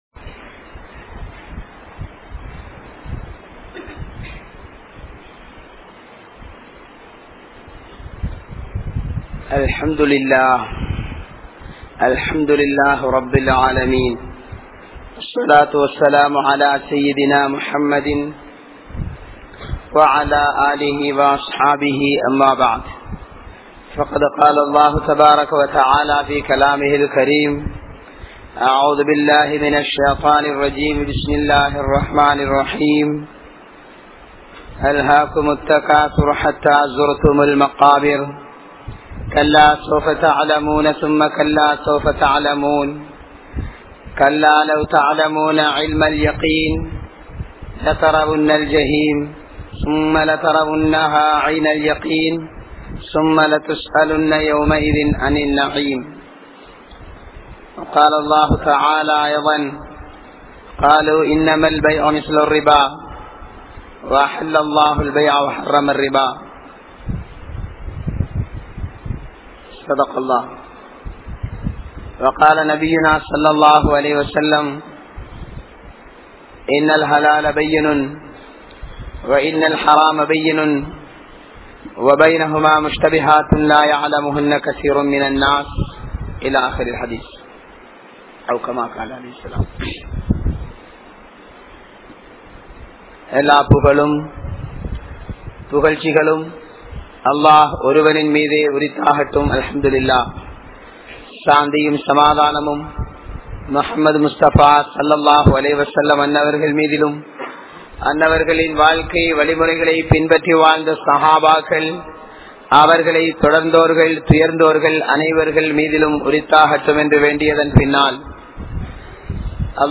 Viyaafaarathil Moasadi Seium Manitharhal (வியாபாரத்தில் மோசடி செய்யும் மனிதர்கள்) | Audio Bayans | All Ceylon Muslim Youth Community | Addalaichenai